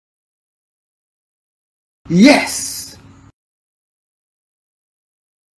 Sonneries » Sons - Effets Sonores » bruitage yes